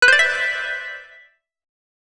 alert2.wav